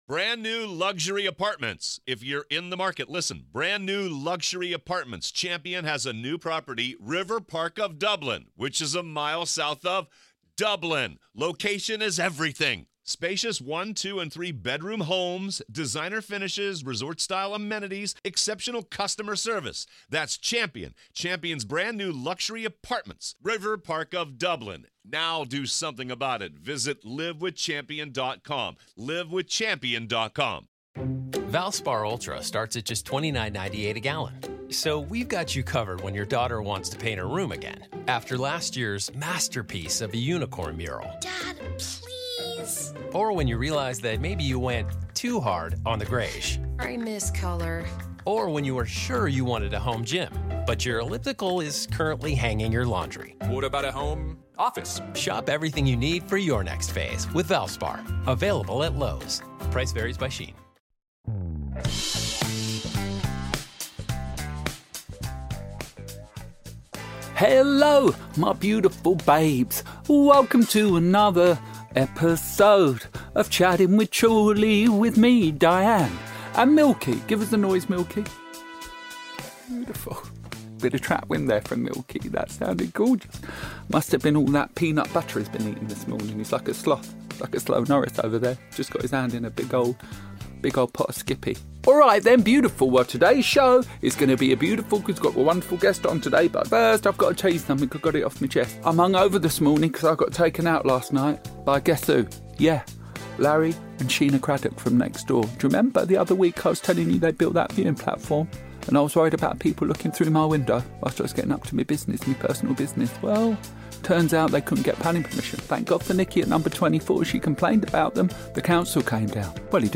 while they chat weekly to beautiful guests each week and ad-lib a unique song for them at the end of the show!